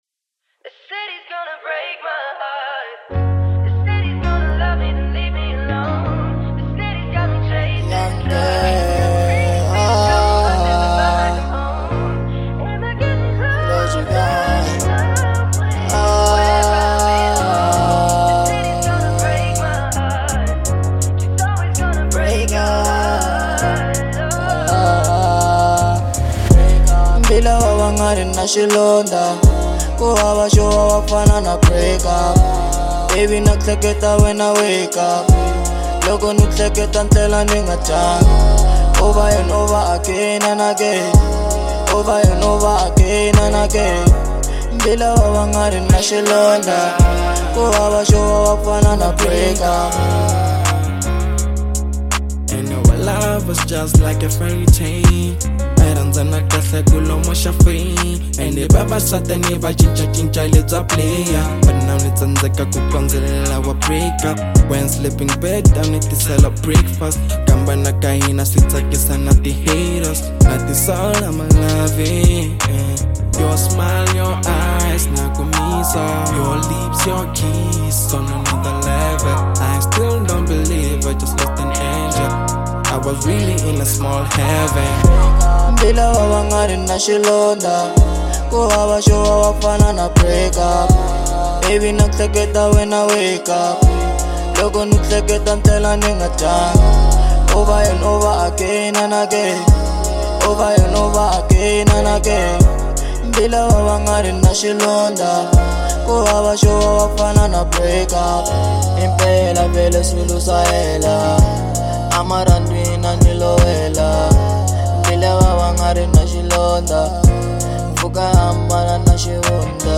02:39 Genre : Hip Hop Size